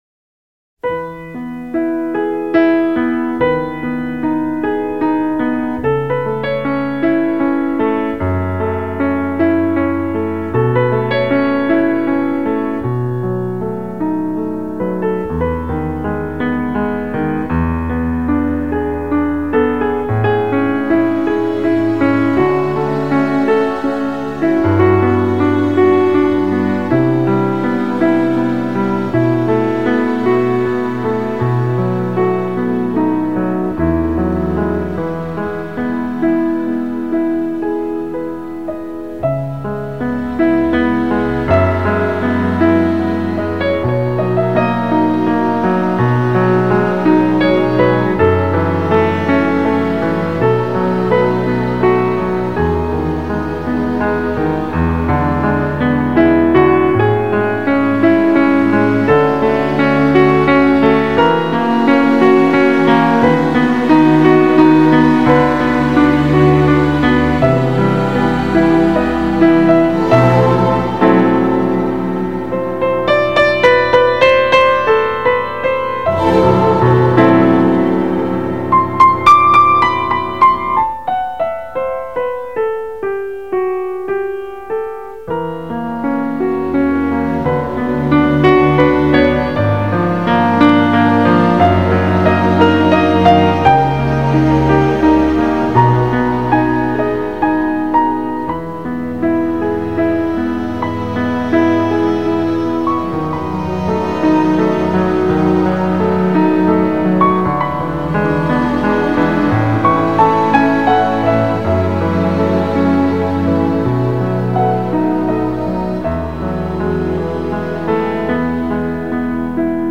경음악
장르: Pop